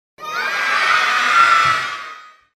Niños Gritando contentos